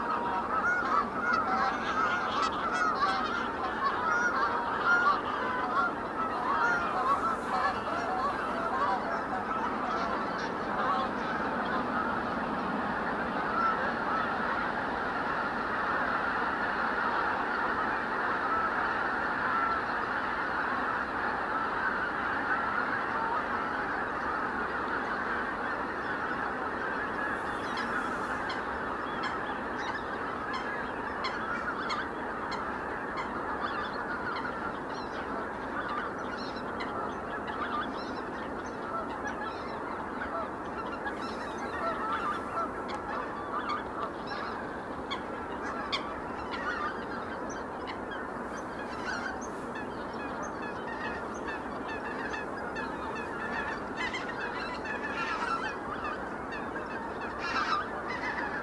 Kolgans
Hun geluid bestaat uit hoge tonen die verschillen van andere soorten.
Hun geluiden zijn niet alleen typerend, maar ook opvallend luid.